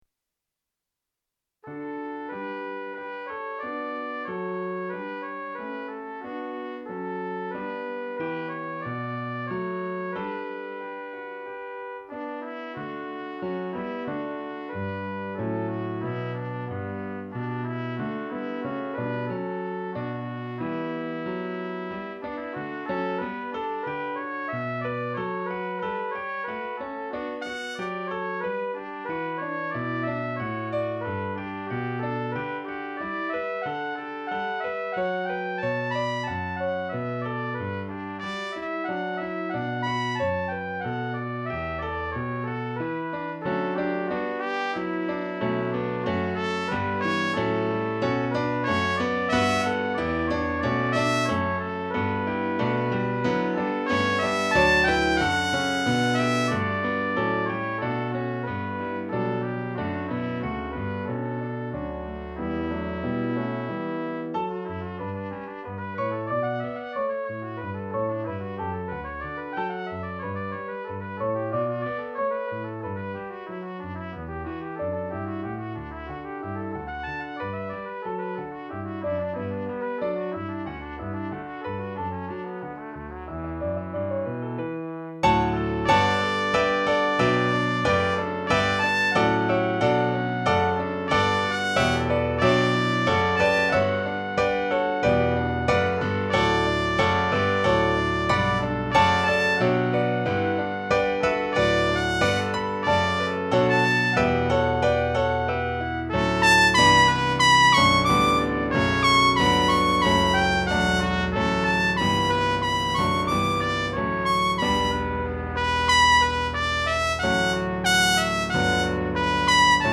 for trumpet and piano